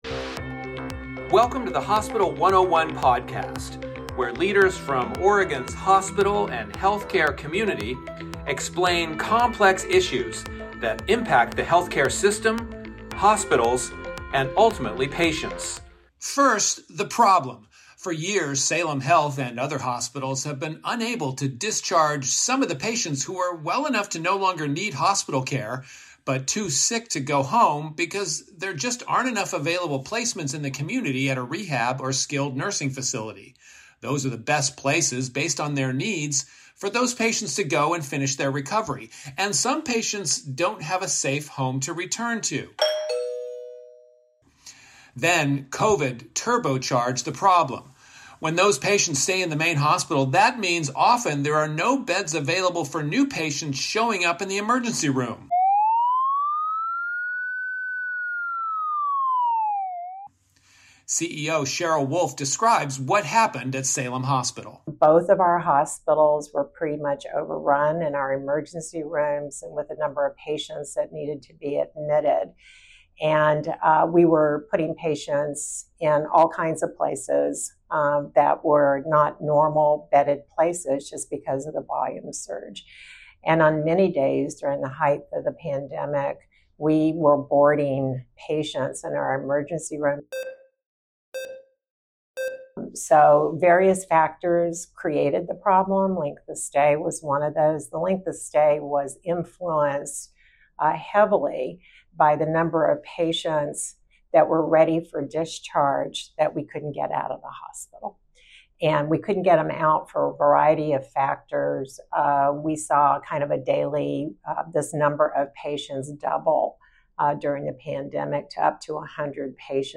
Leaders from Oregon’s hospital and health care community explain complex issues that impact the health care system, hospitals, and ultimately patients.